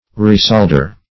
Meaning of ressaldar. ressaldar synonyms, pronunciation, spelling and more from Free Dictionary.
Search Result for " ressaldar" : The Collaborative International Dictionary of English v.0.48: Ressaldar \Res"sal*dar\, n. [Hind. ris[=a]ld[=a]r, fr. ris[=a]lt[=a]troop of horse + Per. d[=a]r holding.]